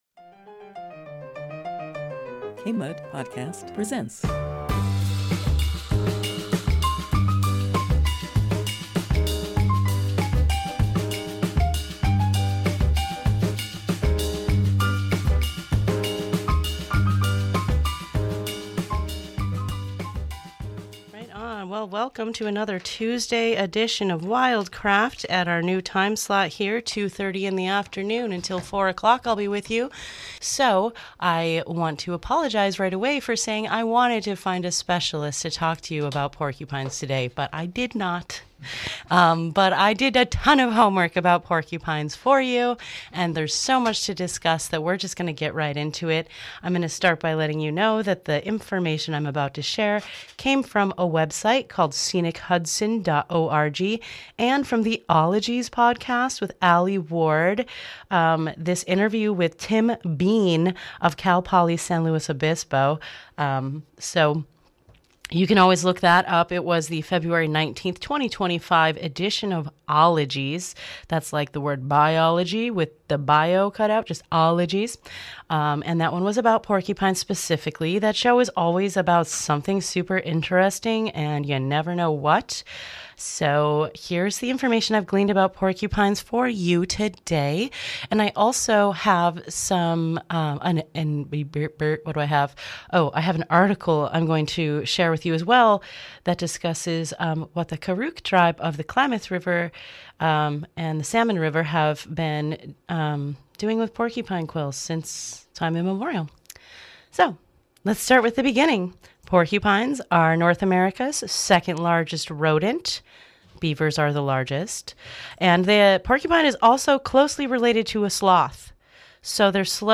These illusive little cuties may be a threatened species in our community and we don't even know! Learn all the facts and hear them talk too!